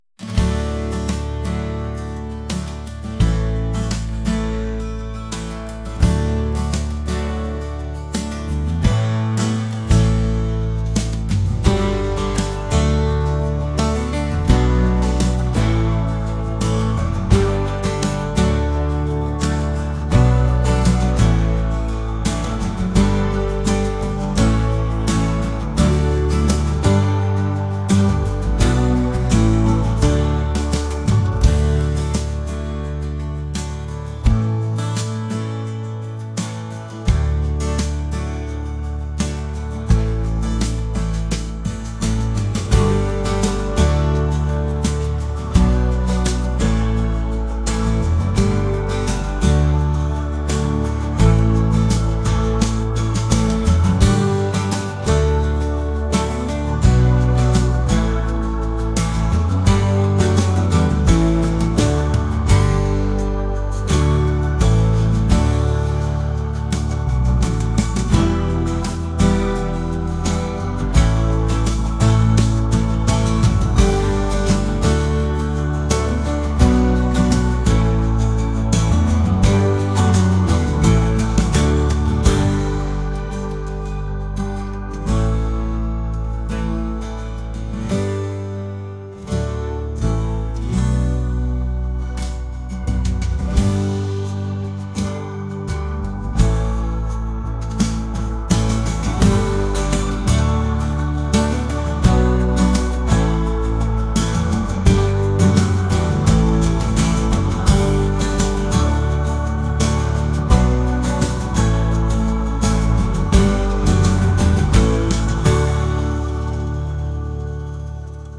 acoustic | laid-back | folk